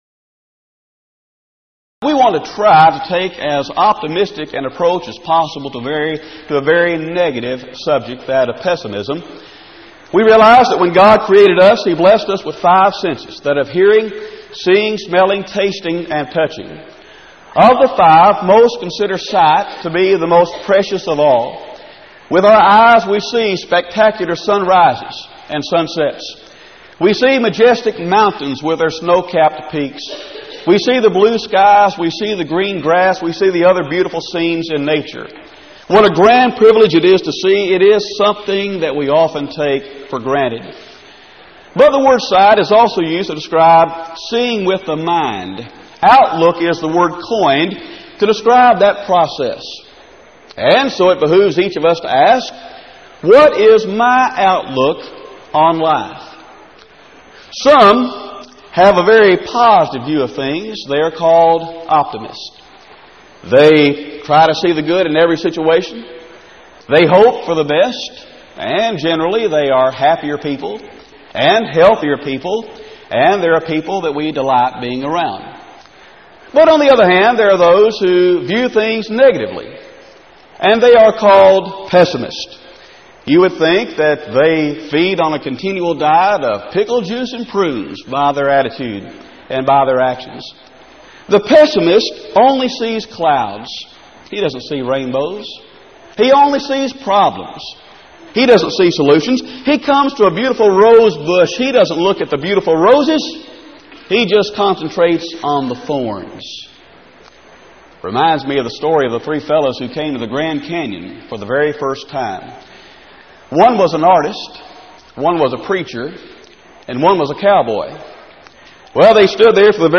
Event: 1997 Power Lectures Theme/Title: Dangerous Ism's